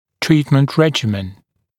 [‘triːtmənt ‘reʤɪmən][‘три:тмэнт ‘рэджимэн]схема лечения, режим лечения